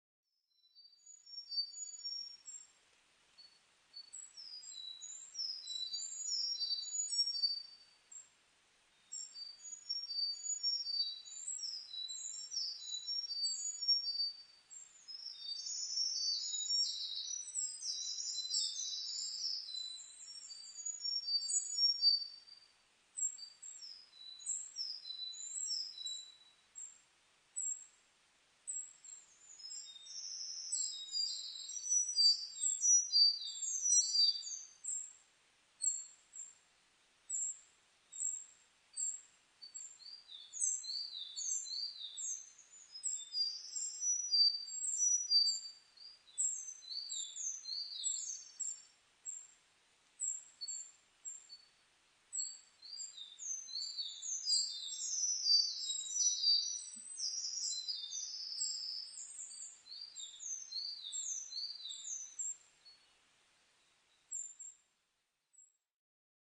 囀り　Singing
日光市稲荷川上流　alt=1190m  HiFi --------------
Mic.: audio-technica AT825
エゾムシクイ・ミソサザイ・コマドリ